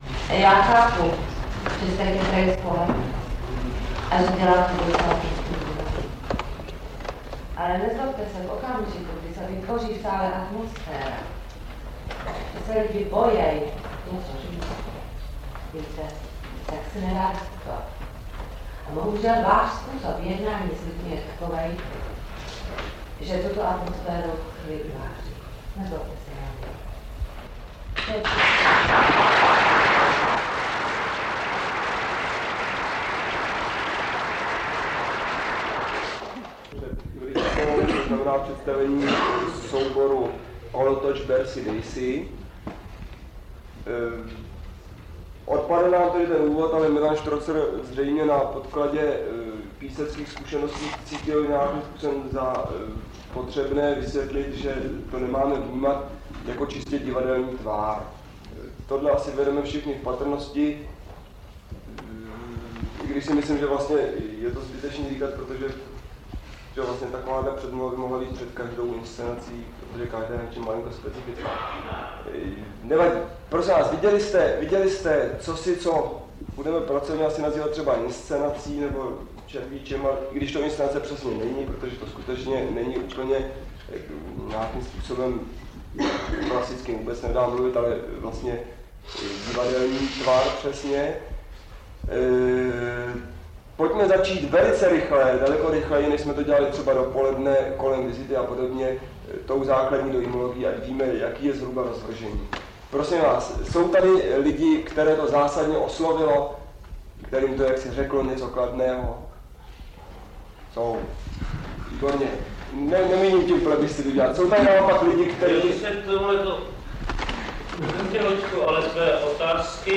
Poděbrady, FEMAD, záznam diskuse k představení divadla Kolotoč, 1984